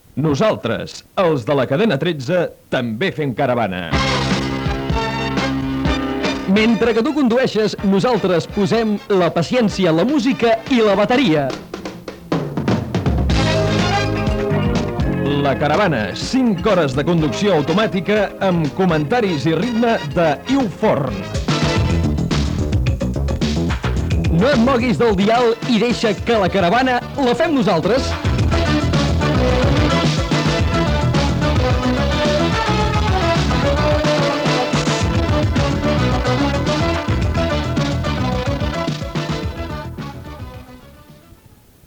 Careta del programa
FM